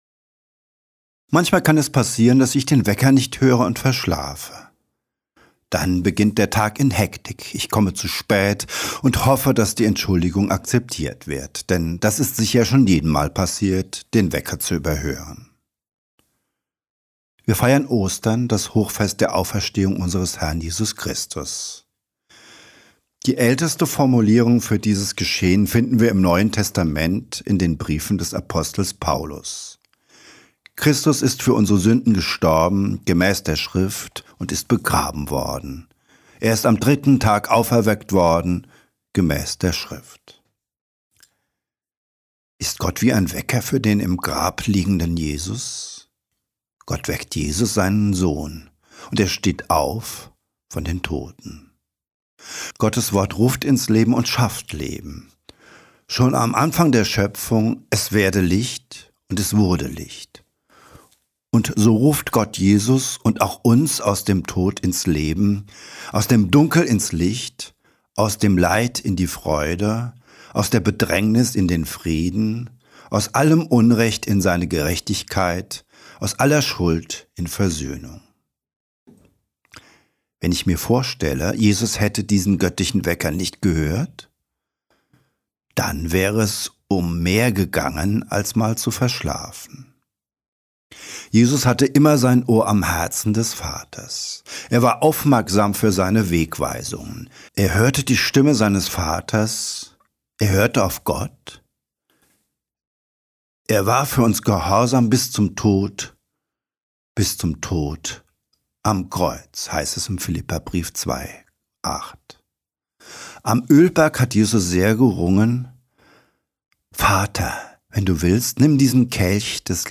Ostersonntag-2025-Predigt-1.mp3